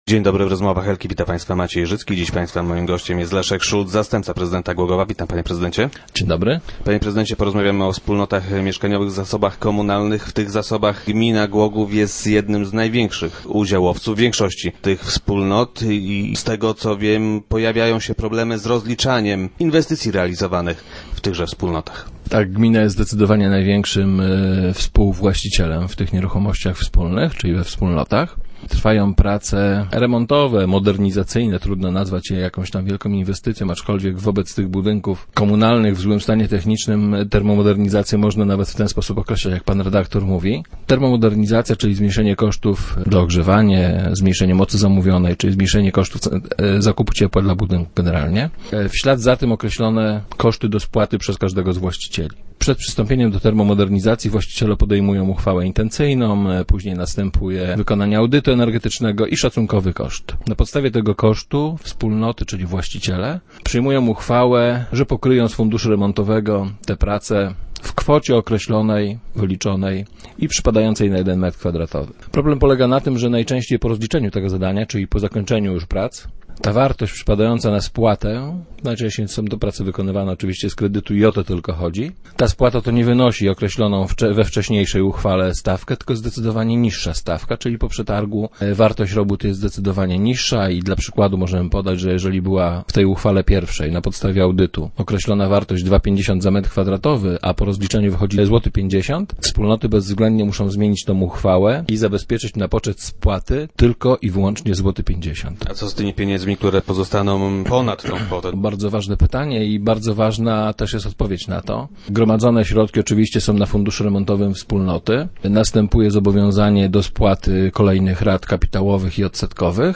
Jak zapowiada Leszek Szulc, zastępca prezydenta Głogowa, jeszcze w tym roku to się zmieni. Wiceprezydent był gościem środowych Rozmów Elki.